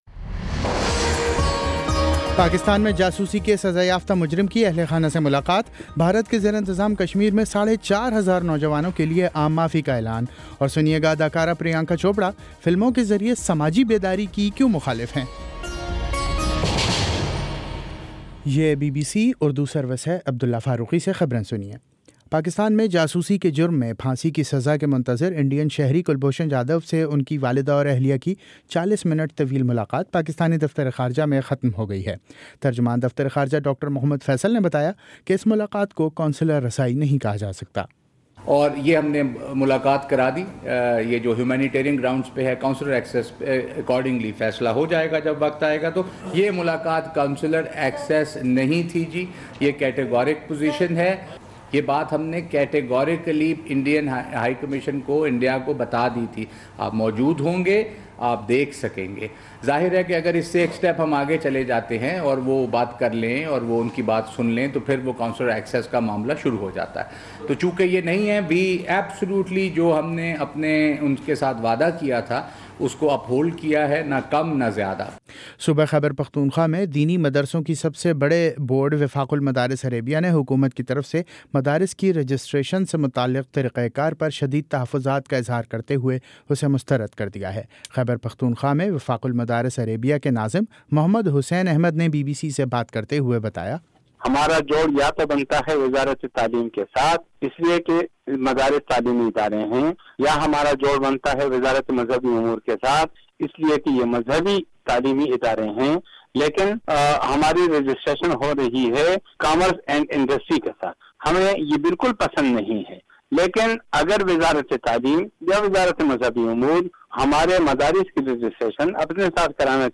دسمبر 25 : شام چھ بجے کا نیوز بُلیٹن